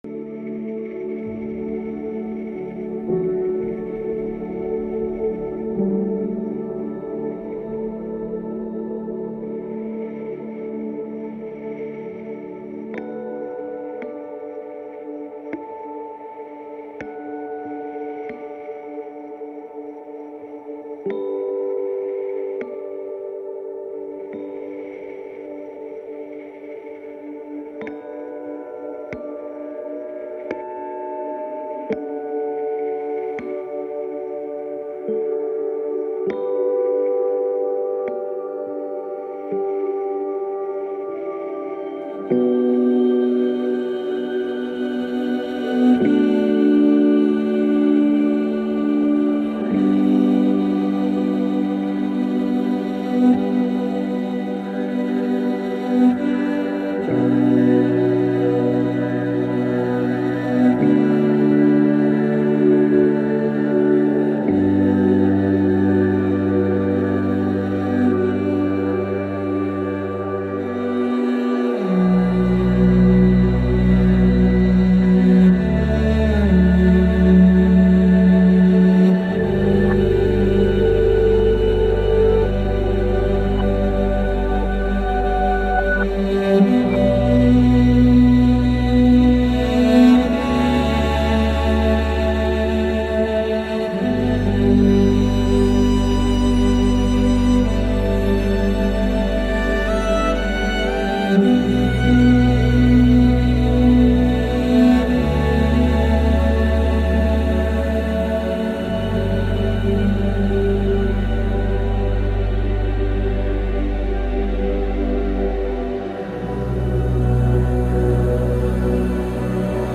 slow-meadow-grey-cloud-lullaby-128-ytshorts.savetube.me_.mp3